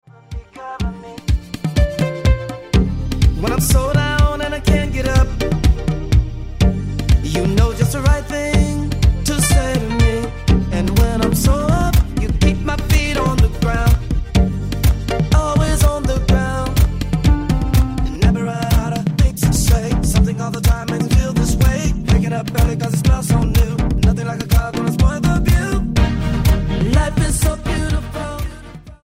R&B
Style: Gospel